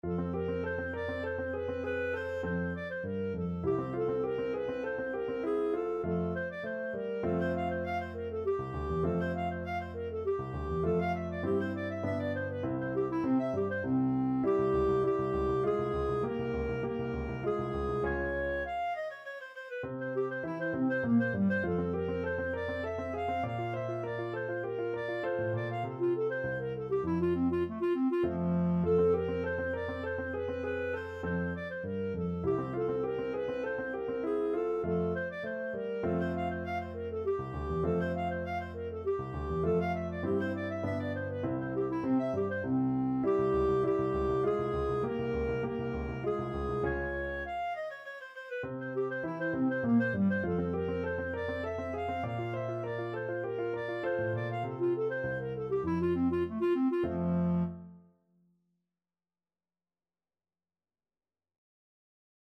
3/4 (View more 3/4 Music)
Classical (View more Classical Clarinet Music)